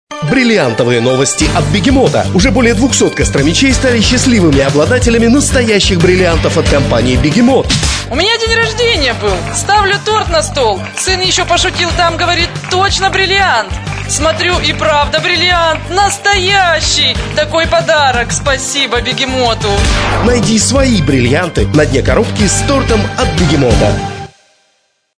Рекламные радио ролики записаны в формате mp3 (64 Kbps/FM Radio Quality Audio).